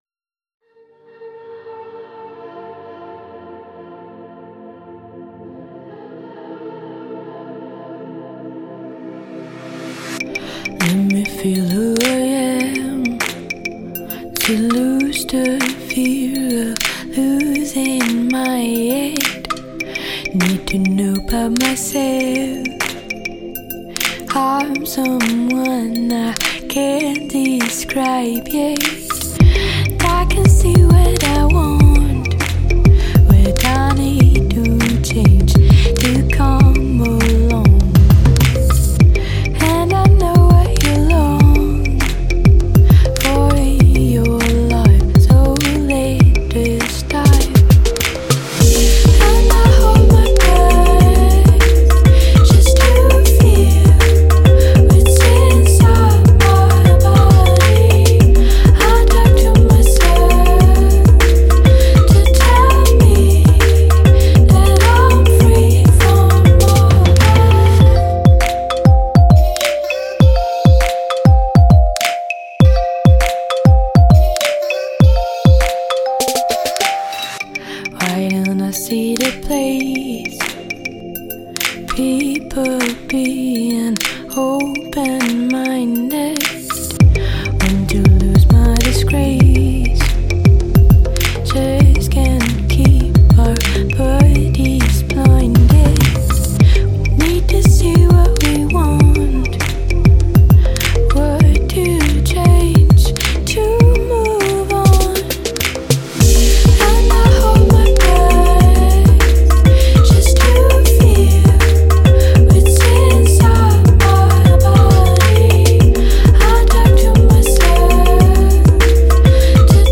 # Electronic